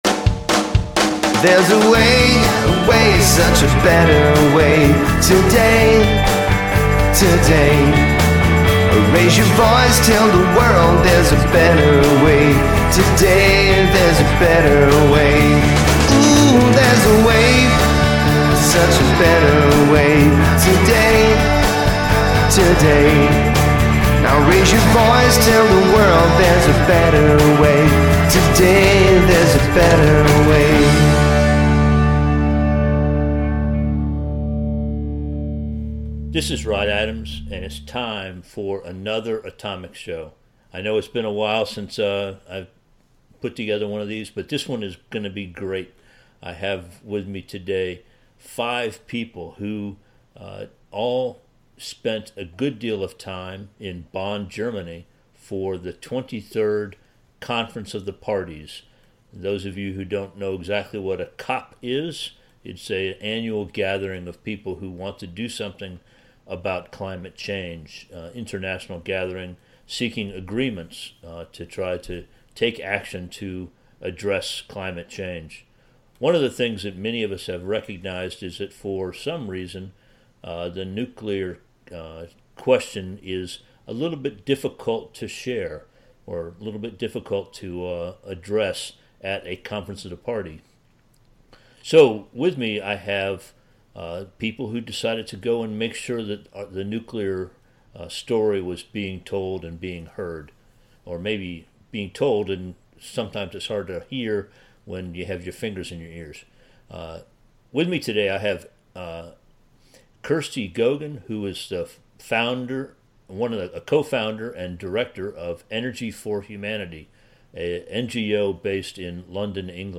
This episode of the Atomic Show is a conversation among five clean energy advocates who attended the COP with the goal of sharing what they know about the ways that nuclear energy can help reduce global emissions while also providing a growing amount of reliable power.